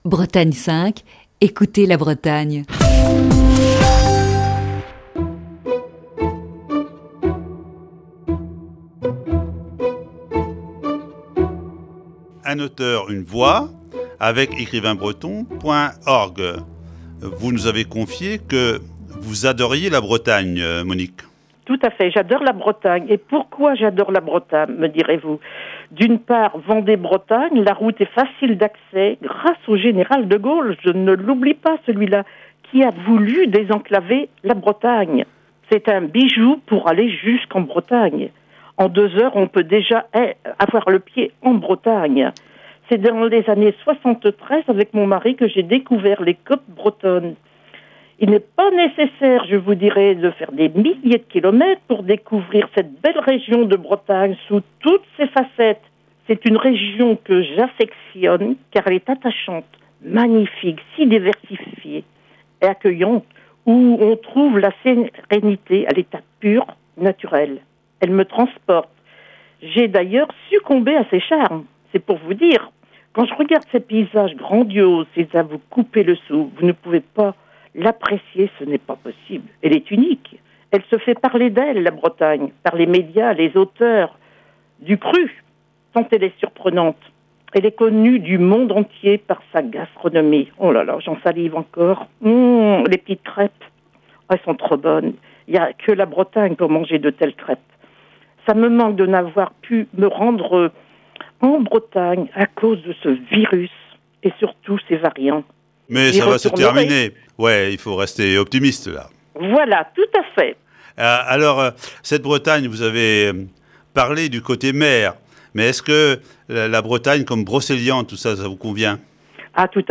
Ce matin, deuxième partie de cet entretien.